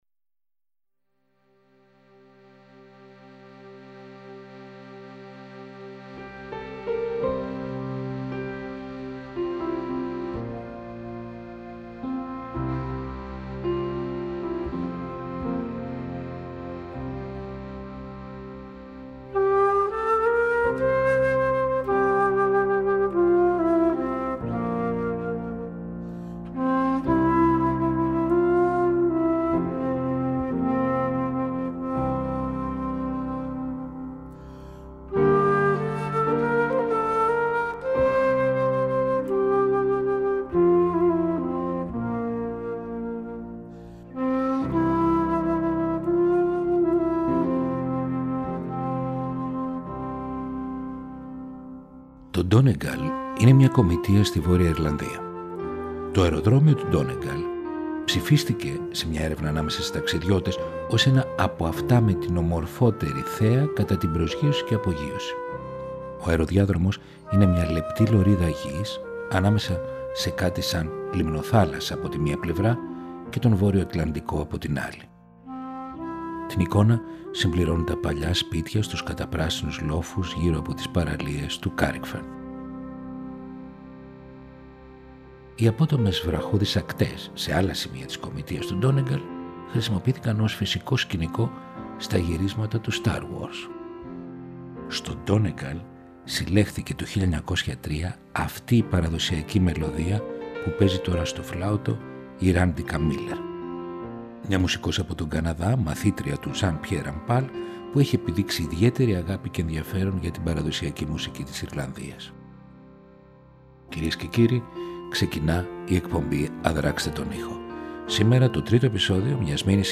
Ιρλανδία, Σκωτία Επεισόδιο 3 ο “Η ολέθρια αγάπη” Μελωδίες και στίχοι που γεννήθηκαν πριν πολλούς αιώνες στα καταπράσινα λιβάδια και στις απόκρημνες ακτές της Ιρλανδίας και της Σκωτίας και συνεχίζουν να συγκινούν και να εμπνέουν ως τις μέρες μας. Μουσικές που έχουν διασωθεί προφορικά και αντανακλούν την κέλτικη ψυχή και τον άλλοτε κραταιό πολιτισμό, που έσπειρε ιδέες σε μεγάλο μέρος της Ευρώπης.